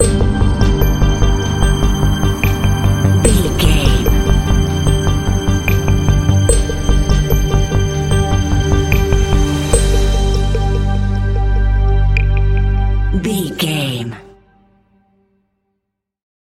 Aeolian/Minor
scary
suspense
foreboding
synthesiser
drums
strings
piano
cinematic
contemporary underscore